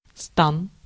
Ääntäminen
Vaihtoehtoiset kirjoitusmuodot (rikkinäinen englanti) mercheen masheen Synonyymit computer apparatus box Ääntäminen US : IPA : /mə.ˈʃiːn/ UK : IPA : [mə.ˈʃiːn] Lyhenteet ja supistumat (laki) Mach.